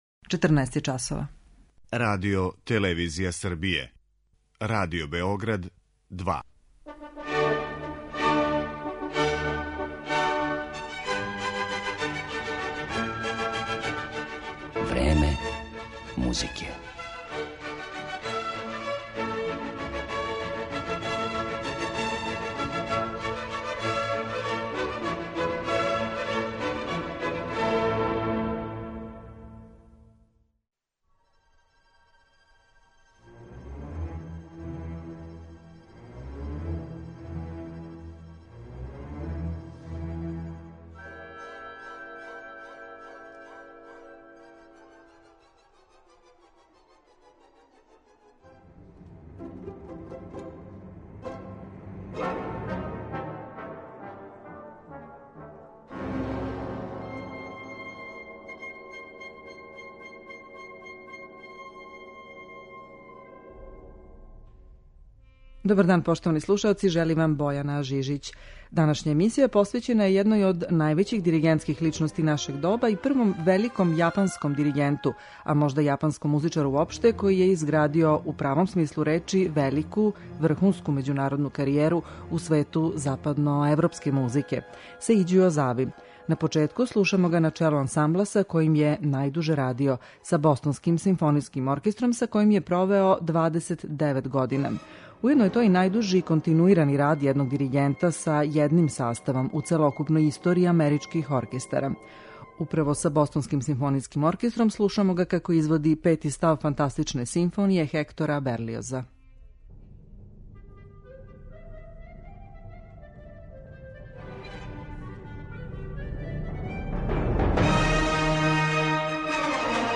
Слушаћемо га са ансамблом на чијем је челу био 29 година, Бостонским симфонијским оркестром, али и са Саито Кинен оркестром, Чикашким симфонијским оркестром, Берлинском и Бечком филхармонијом.